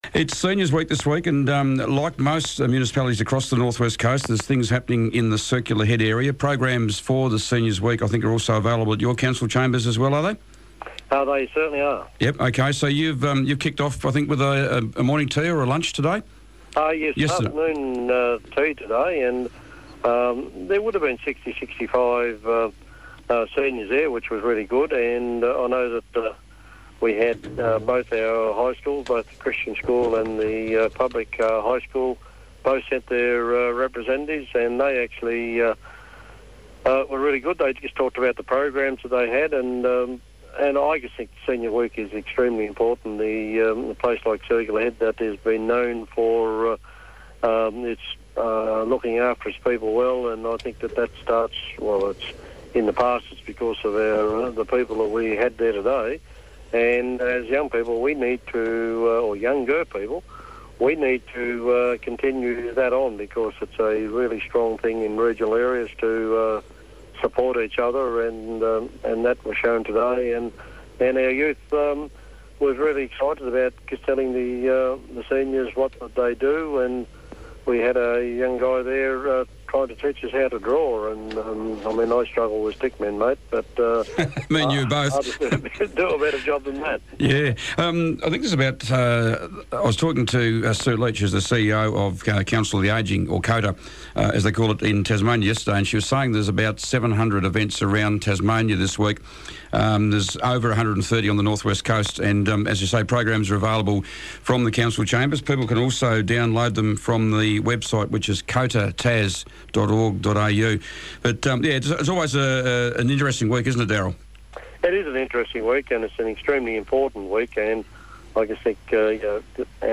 Circular Head Mayor Darryl Quilliam was today's Mayor on the Air. Darryl spoke about Seniors Week activities, funding for the new Smithton Swimming Pool, and the CHartchibald art prize . . .